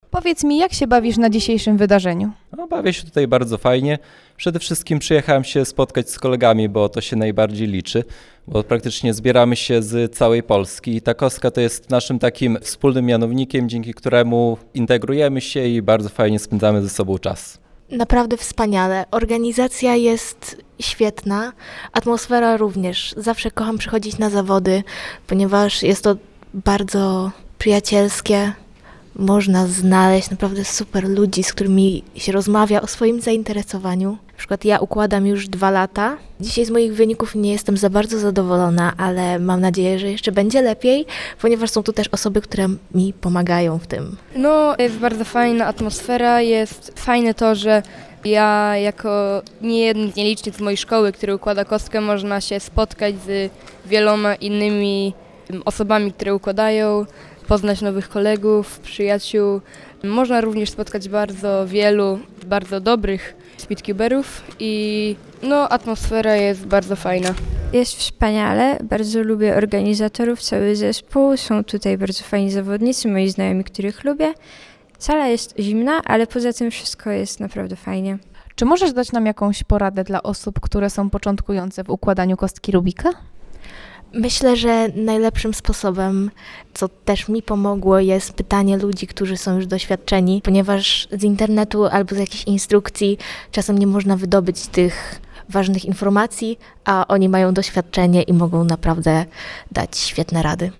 Nasza reporterka rozmawiała także z innymi uczestnikami zawodów Olsztyn Open 2024.